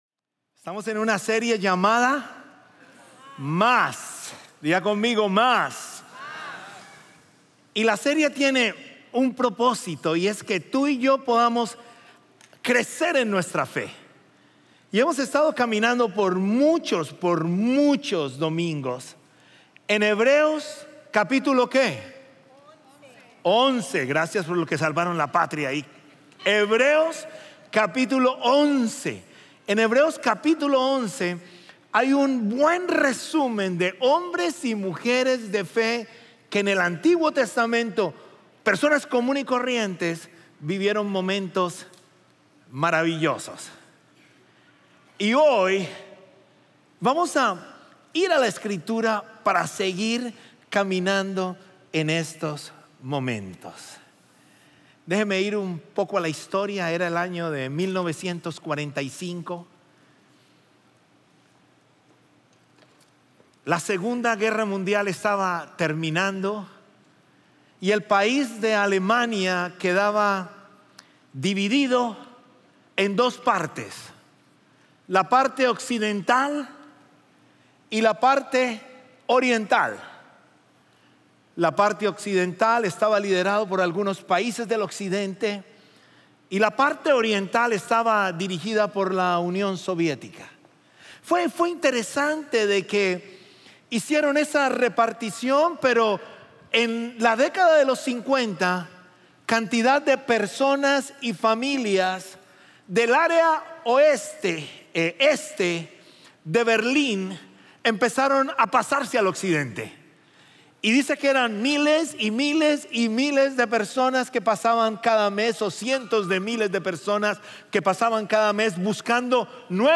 Sermones Dominicales – Media Player